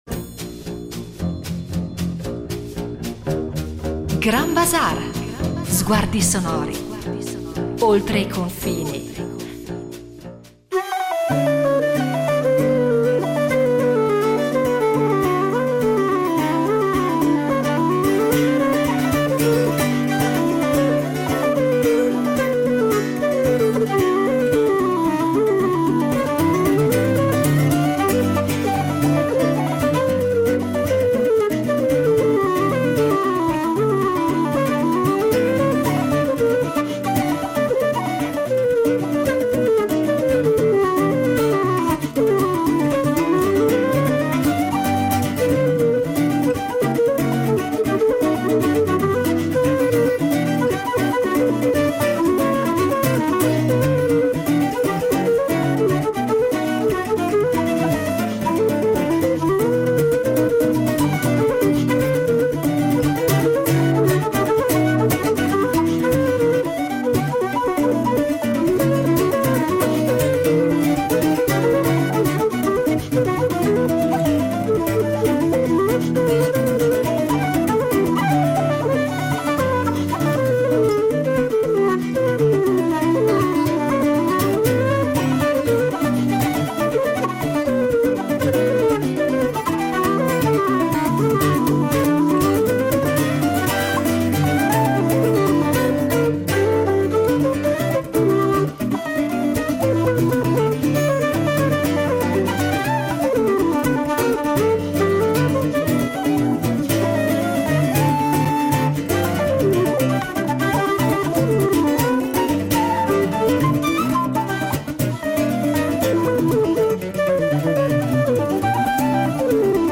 Il flauto irlandese è un flauto traverso in legno, correntemente usato nella musica britannica e irlandese.
Questa disposizione, messa a punto dal virtuoso Inglese Charles Nicholson , dà loro un suono potente e leggermente roco, quando viene spinto.